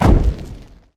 amber_dry_fire_01.ogg